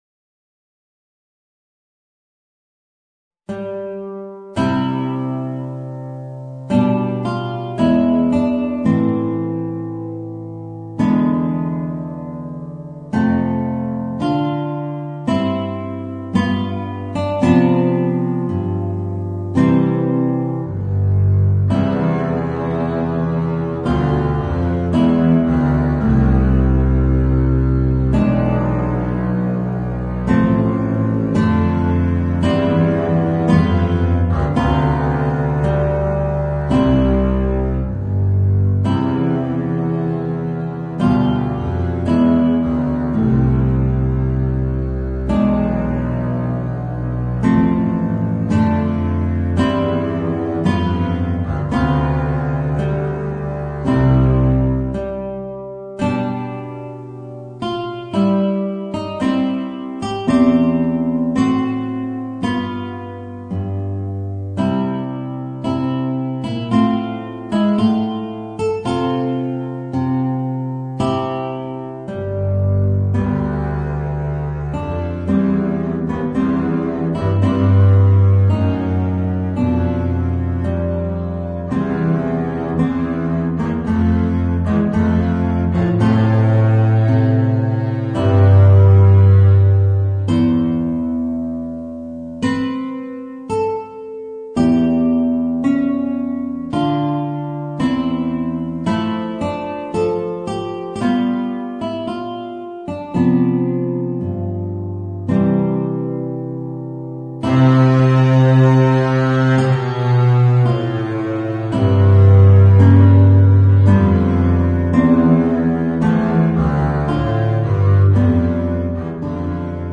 Voicing: Guitar and Contrabass